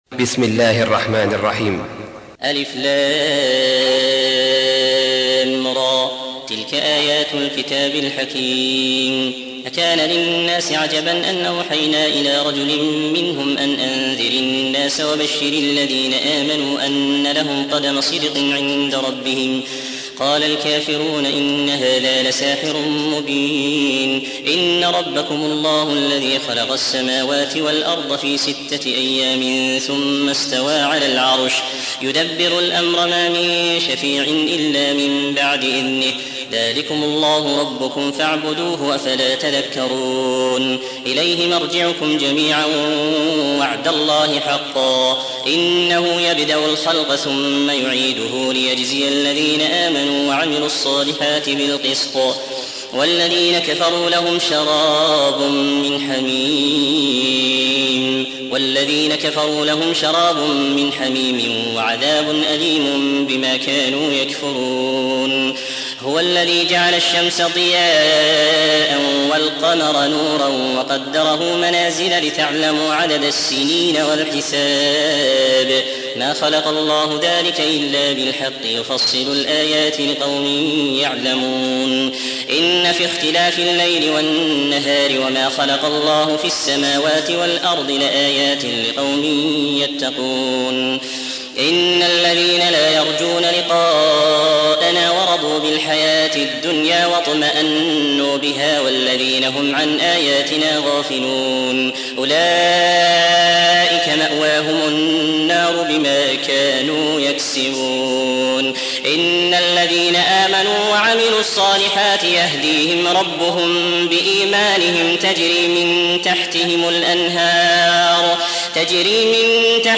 10. سورة يونس / القارئ